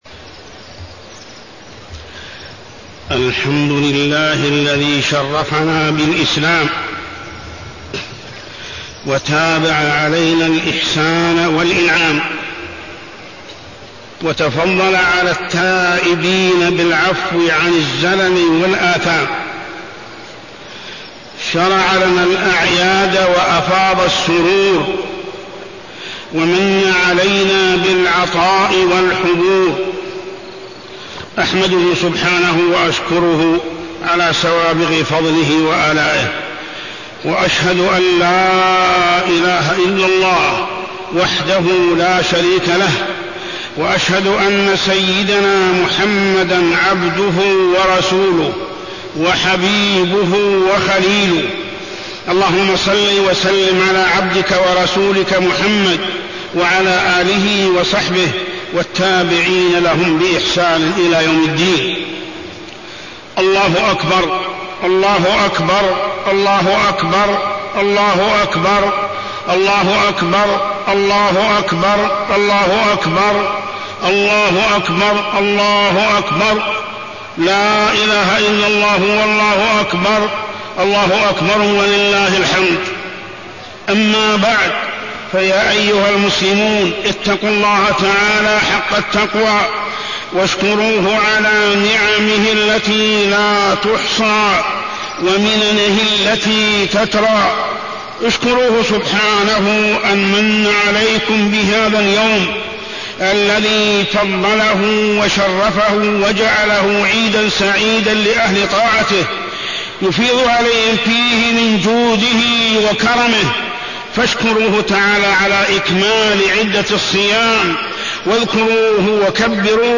خطبة عيد الفطر -الإنحراف العقدي
تاريخ النشر ١ شوال ١٤٢٢ هـ المكان: المسجد الحرام الشيخ: محمد بن عبد الله السبيل محمد بن عبد الله السبيل خطبة عيد الفطر -الإنحراف العقدي